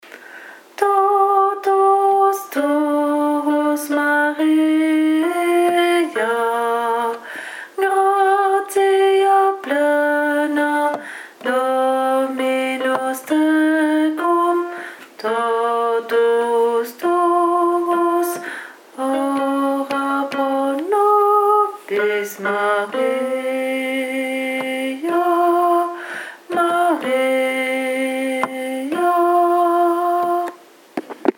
BASSE